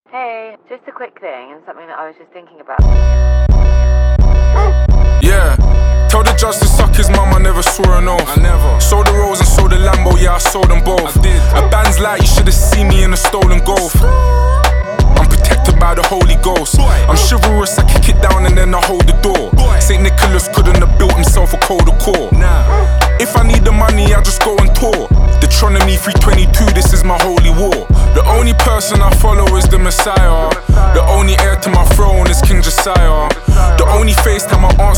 Hip-Hop Rap
Жанр: Хип-Хоп / Рэп